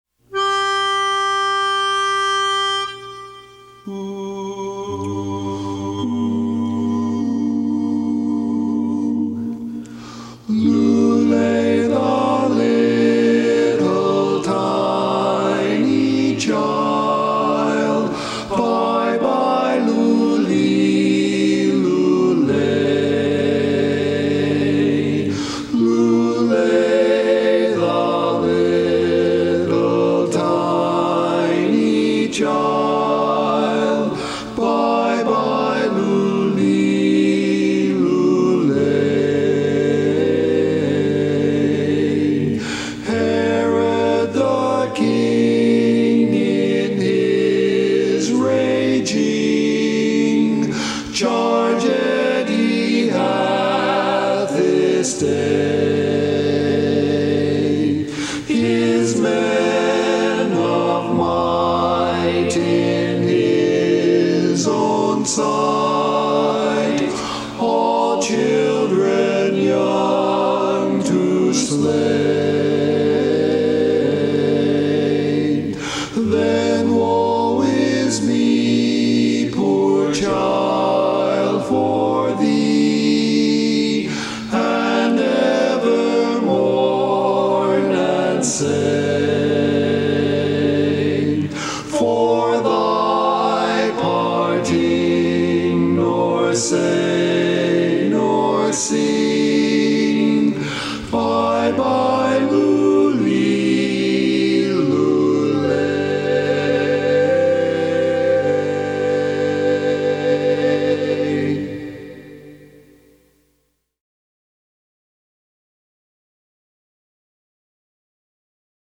Christmas Songs
Barbershop
Bari